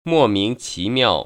[mò míng qí miào] 모밍치미아오  ▶